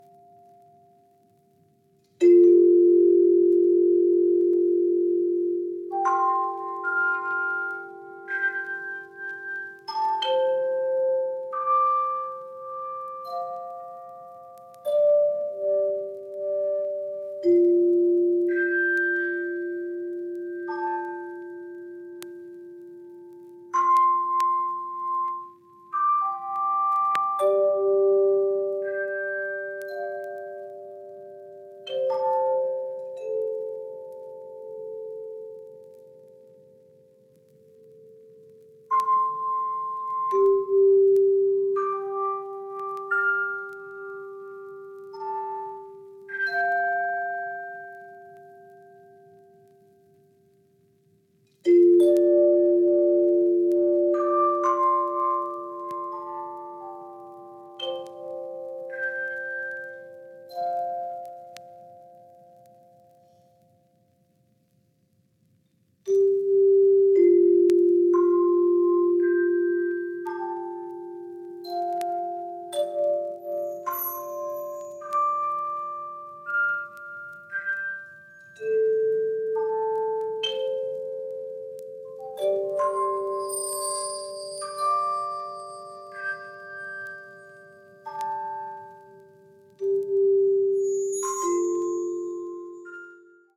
による、単音打楽器＜クワイヤーチャイム＞の演奏。
体にしみる音の響きが急がず、慌てず、流れてきます。